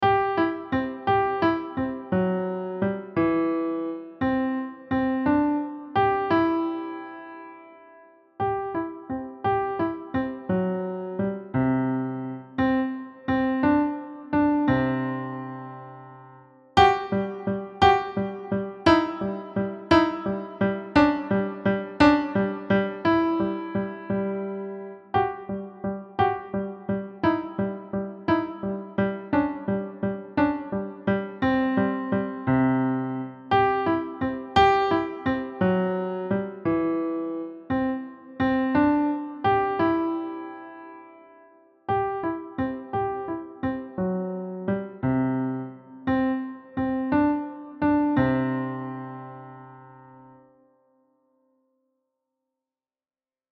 Key: C position with the written accidental – F♯
Time Signature: 3/4
Level: Elementary